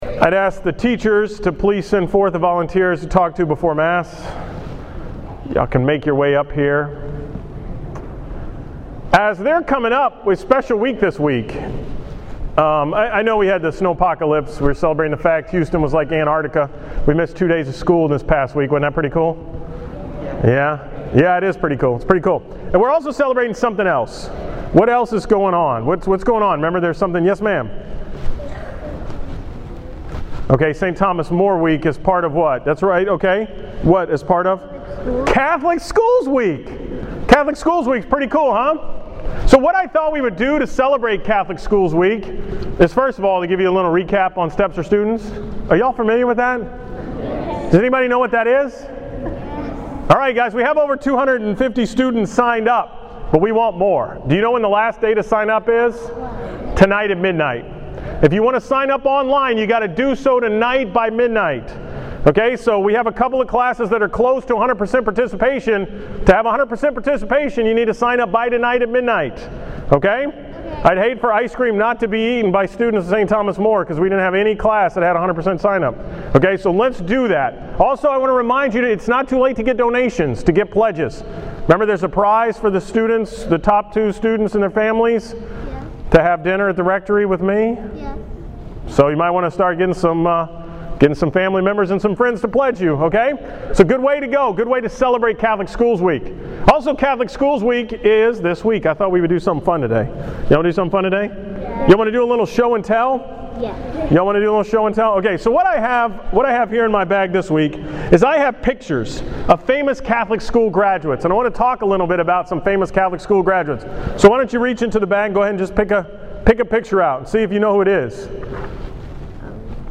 Homily from the Catholic Schools week on January 30, 2014
Category: 2014 Homilies, School Mass homilies